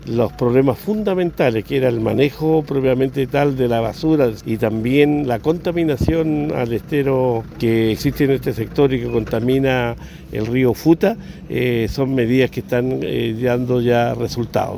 El gobernador de Los Ríos, Luis Cuvertino, recalcó que se están dando los resultados para prevenir la contaminación en el estero El Mosco.